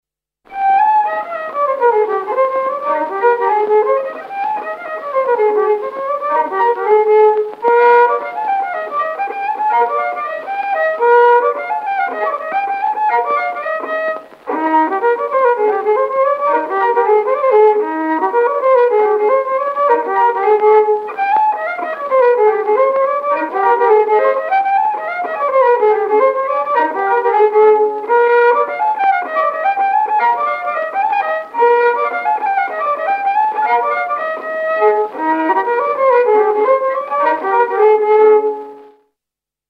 29 Labajalg Ai-niga-naga.mp3